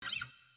Audio_Sample_-_WL4_Guitair_Fret.oga